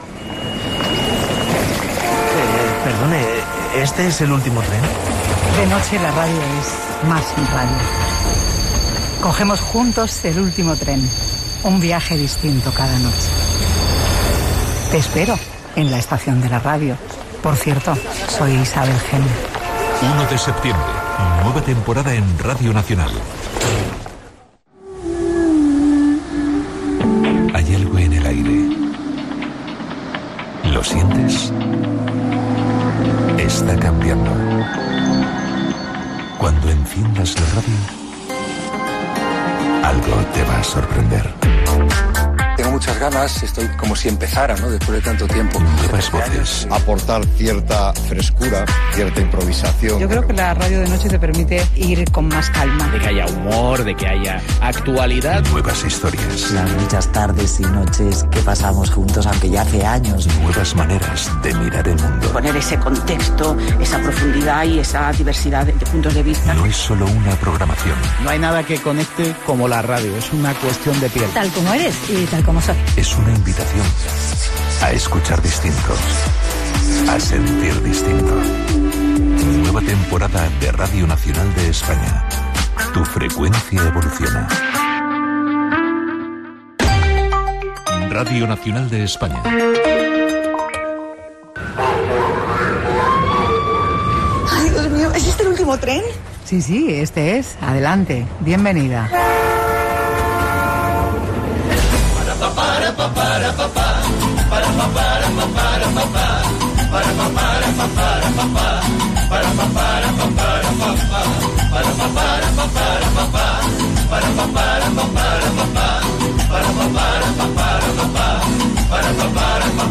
Promoció del programa "El último tren" i de la nova programació de RNE, Indicatiu de la ràdio, cançó d'una xirigota de Cadis
Gènere radiofònic Entreteniment